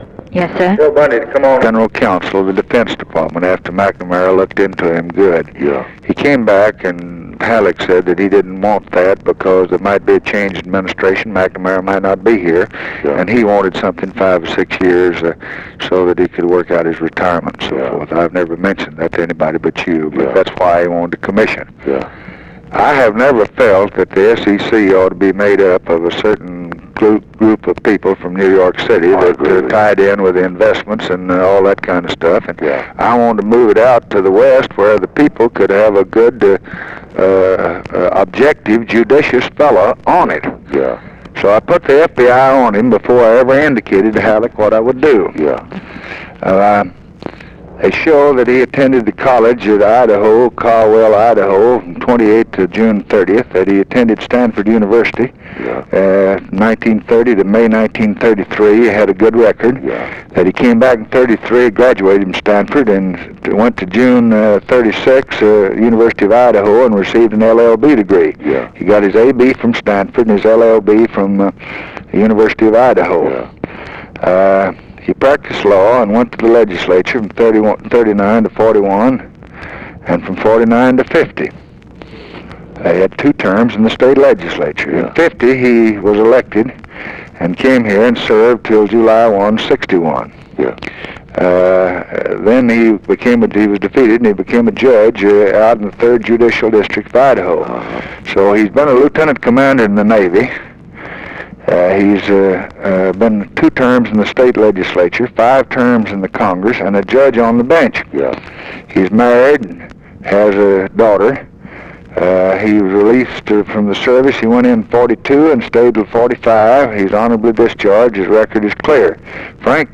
Conversation with EVERETT DIRKSEN, June 22, 1964
Secret White House Tapes